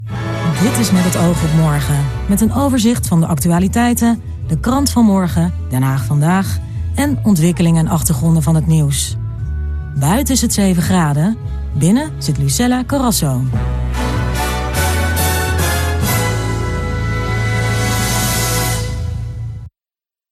Een stem die je er zo uitpikt. Uniek en toegankelijk tegelijk, dat is heel bijzonder.
Hoe het begin van Met Het Oog op Morgen voortaan klinkt is hieronder te horen.